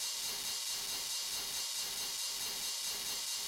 STEADY RID-R.wav